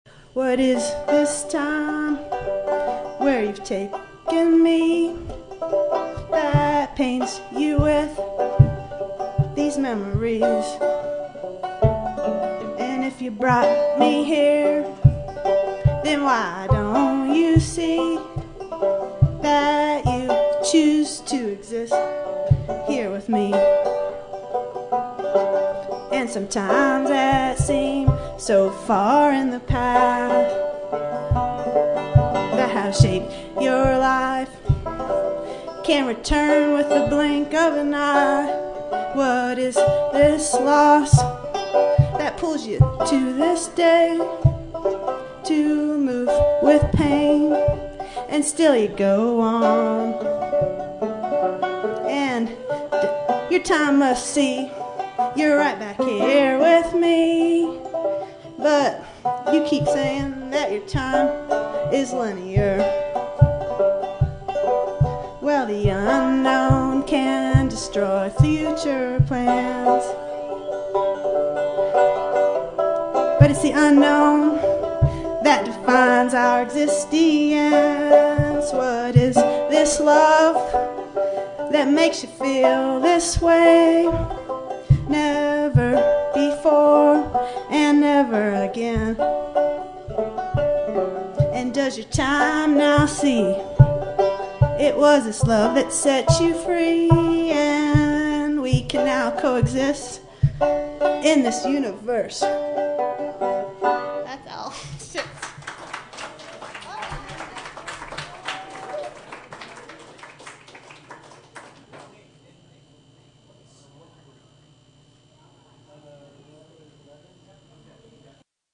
Mercury Café Open Mic Nite